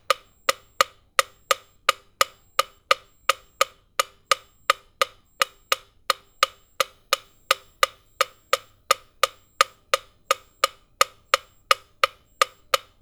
Tiempo allegro en un metrónomo
metrónomo